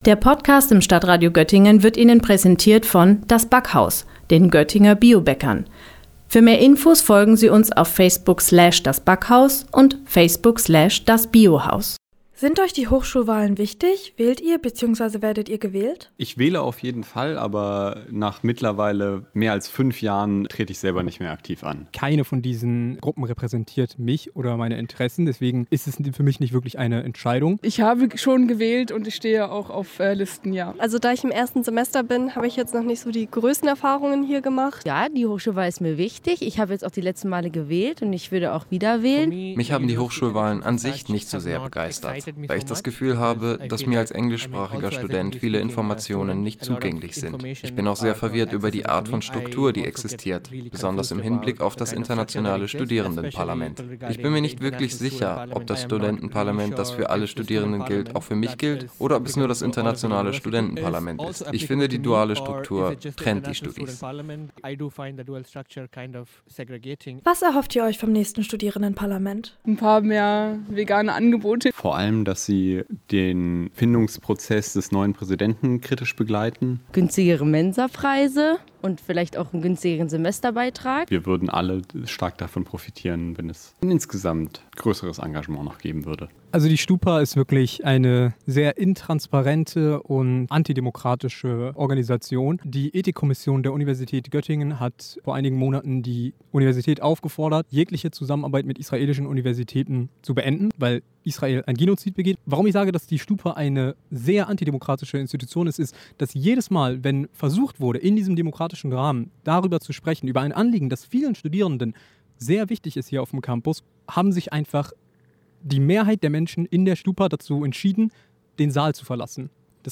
Sendung: Umfragen Redaktion